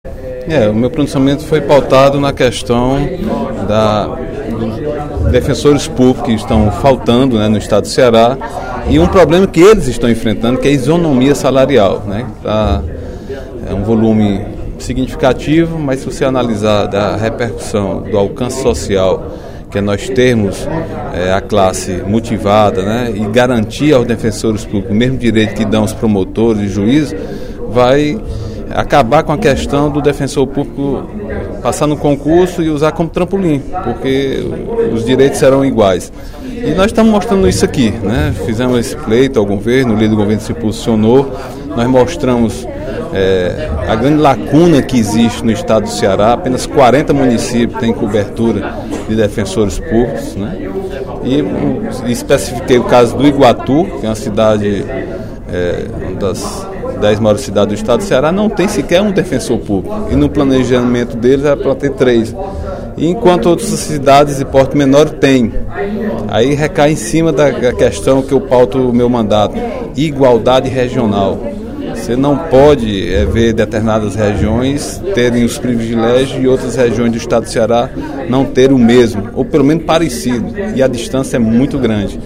Durante o primeiro expediente da sessão plenária desta sexta-feira (27/03), o deputado Agenor Neto (PMDB) defendeu a isonomia salarial para a Defensoria Pública do Estado do Ceará.